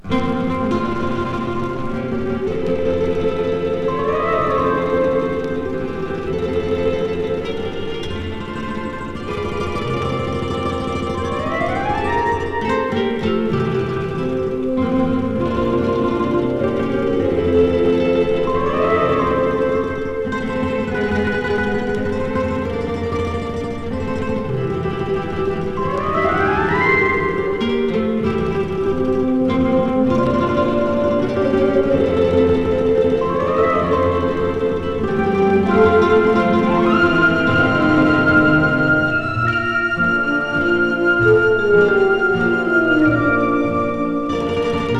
Jazz, Pop, World, Easy Listening　USA　12inchレコード　33rpm　Stereo